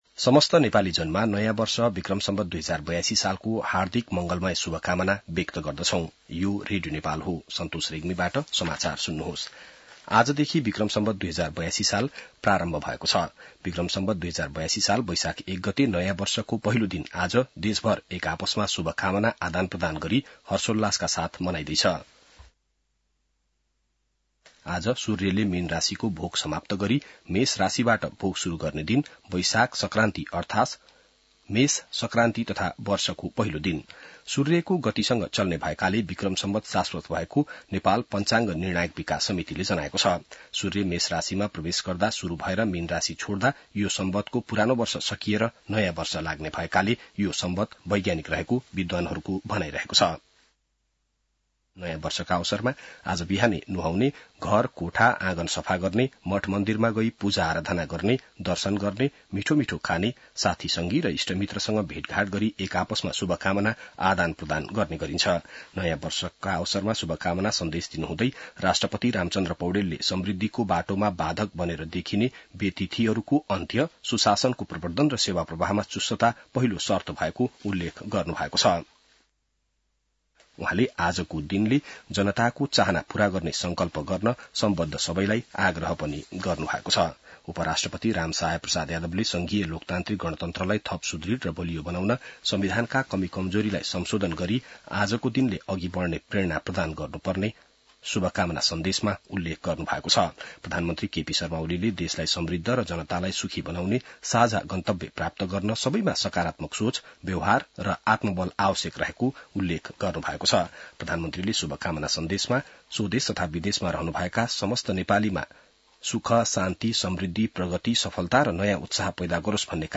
बिहान ६ बजेको नेपाली समाचार : १ वैशाख , २०८२